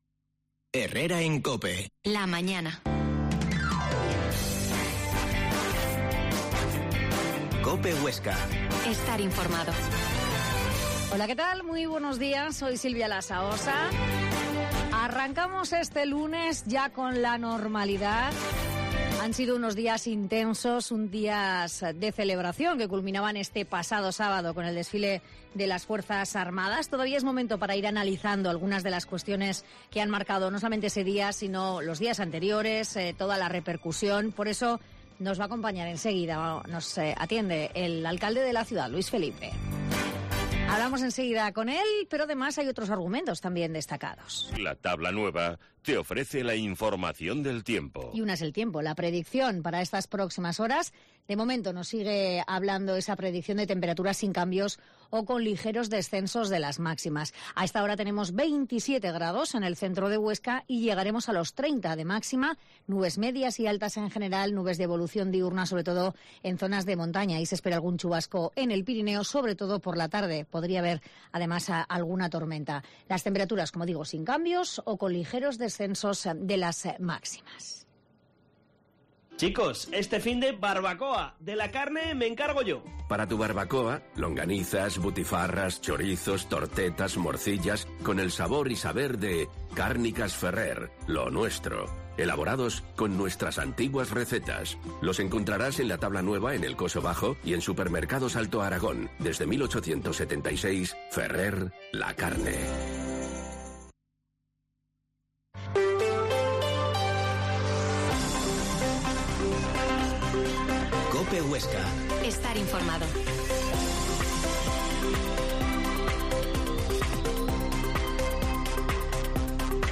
Herrera en COPE Huesca 12.50h Entrevista al alcalde de Huesca Luis Felipe